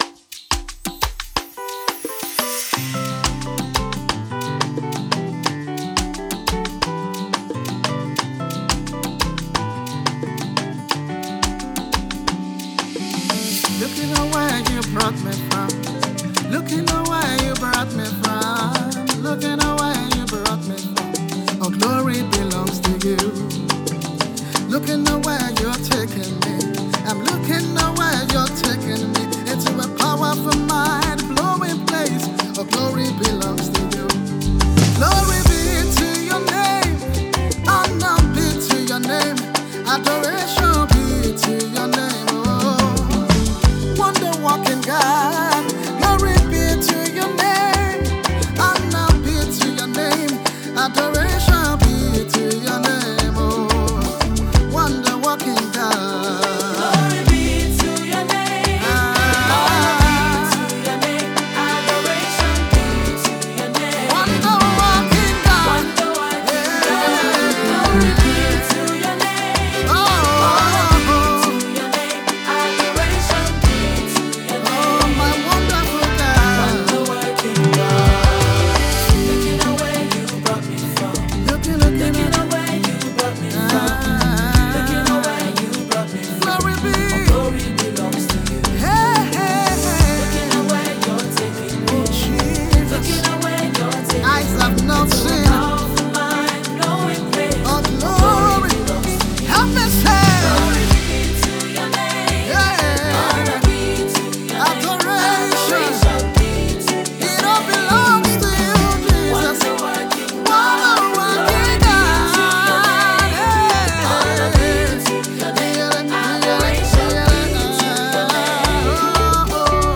• Singer